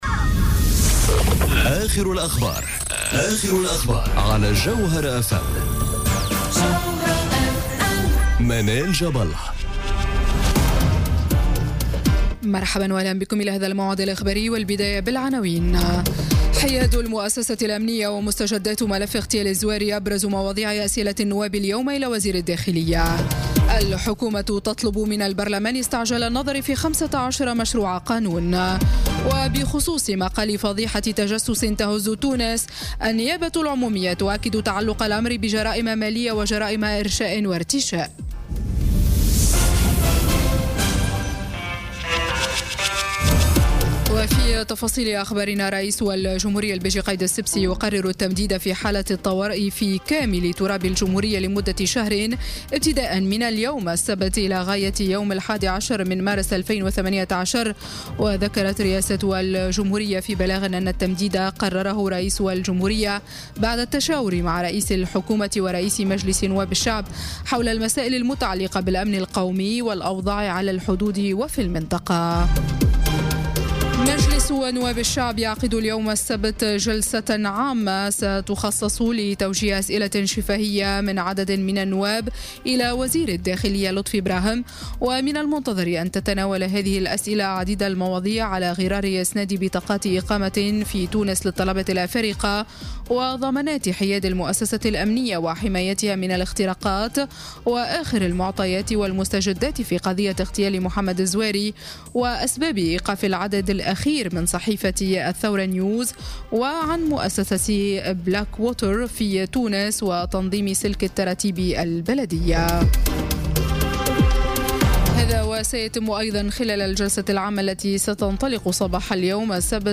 نشرة أخبار منتصف الليل ليوم السبت 10 فيفري 2018